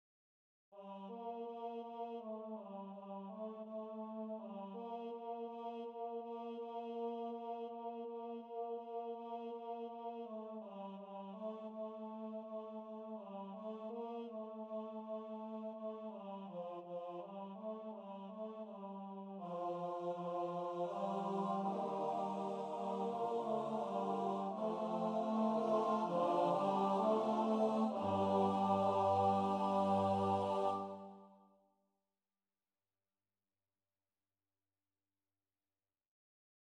Extended chant)Cel.